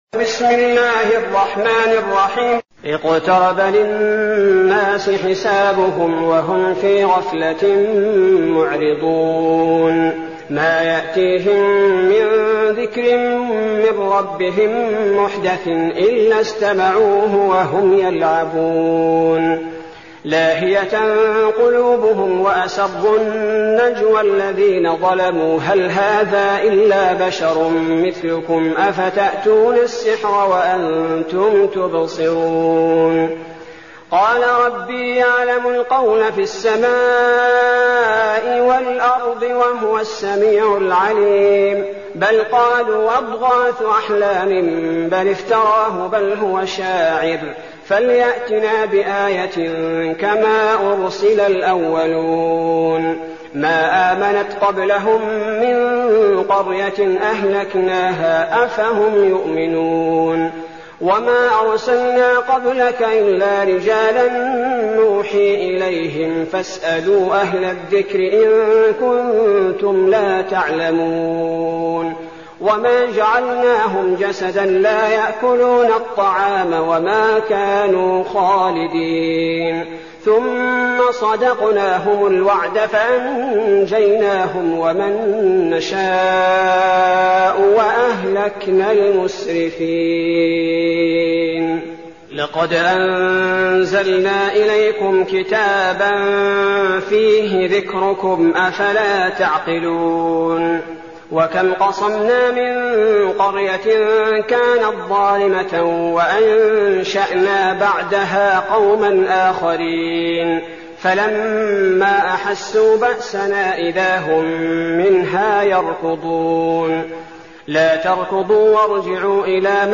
المكان: المسجد النبوي الشيخ: فضيلة الشيخ عبدالباري الثبيتي فضيلة الشيخ عبدالباري الثبيتي الأنبياء